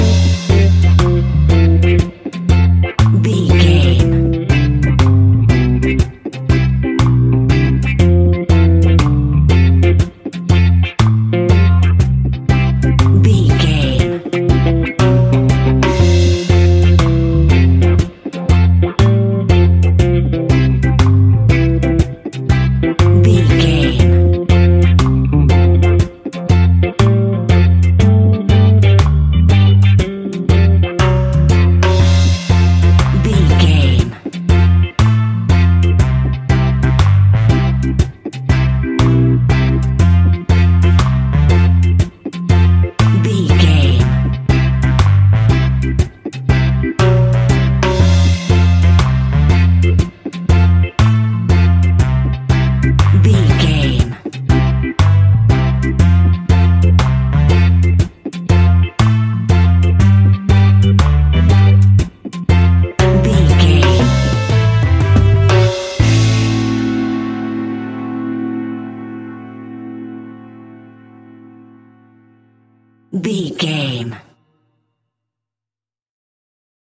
Classic reggae music with that skank bounce reggae feeling.
Aeolian/Minor
instrumentals
laid back
off beat
drums
skank guitar
hammond organ
percussion
horns